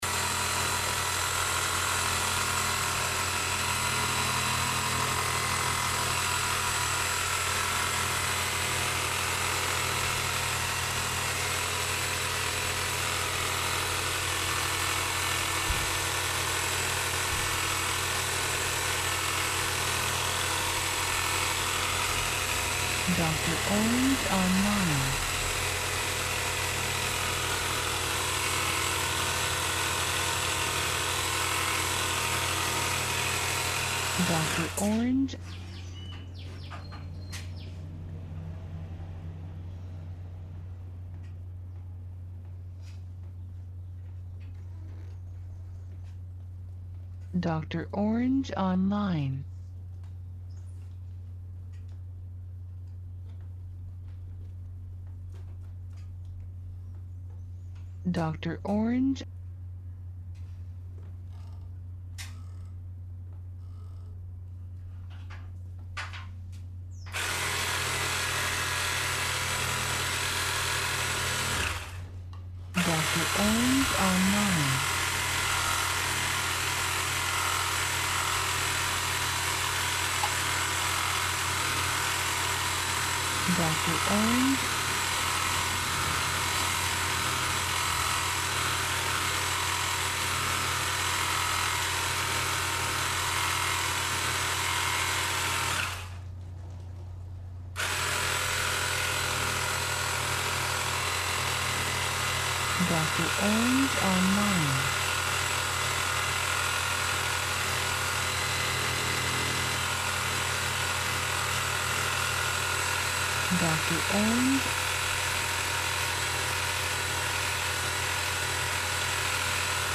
Ambiente Estéreo Interior Reparación AC
INTERIOR REPARACION DE AIRE ACONDICIONADO, INTERACCION, AMBIENTE, MAQUINARIA, MANIPULACION HERRAMIENTAS.
Archivo de audio MONO, 96Khz – 24 Bits, WAV.
INTERIOR-REPARACION-DE-AIRE-ACONDICIONADO_INTERACCION_AMBIENTE-96KHZ_.mp3